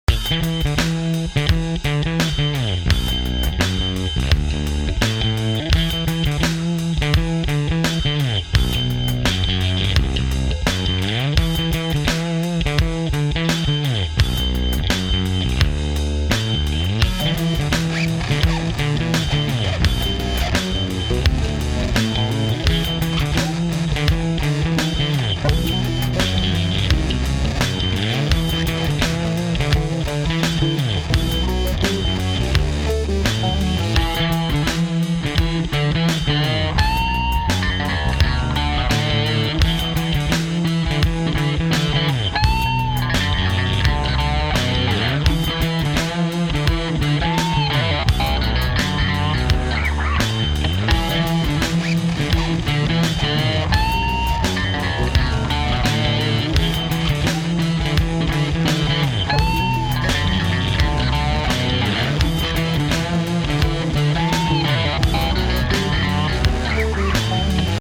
3 bass grooves